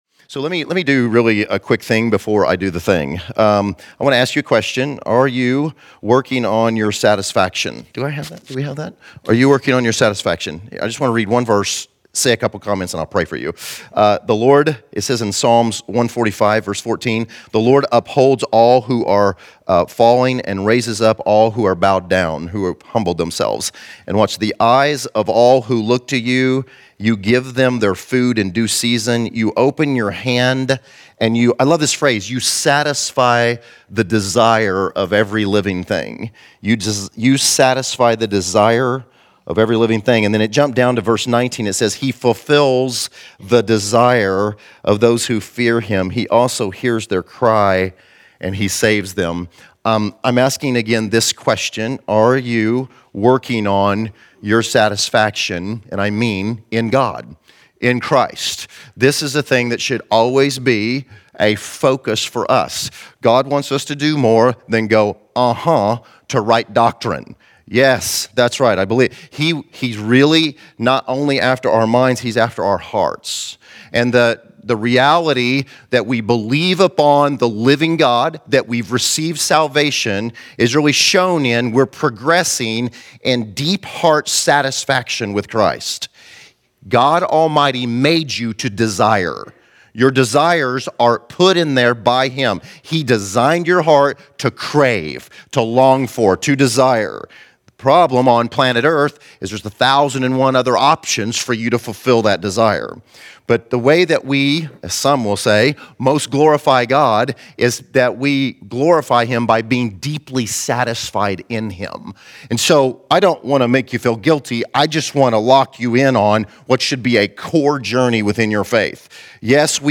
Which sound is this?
Category: Primers